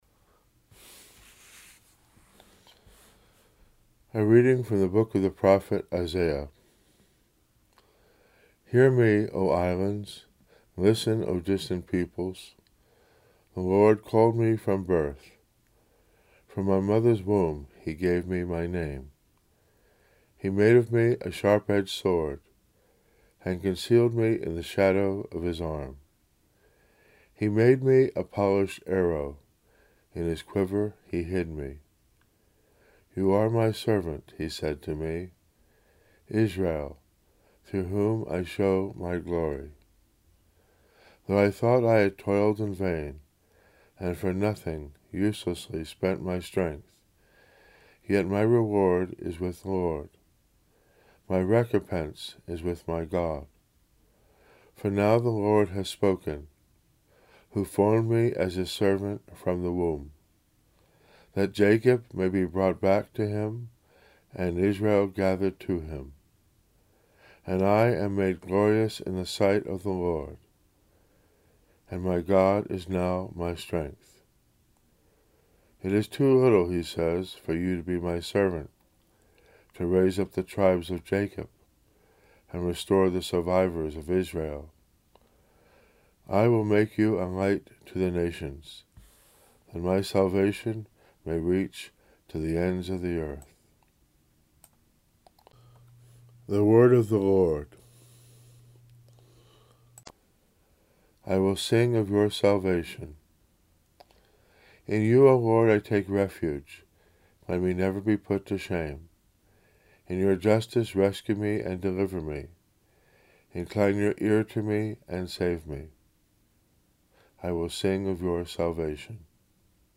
Homily: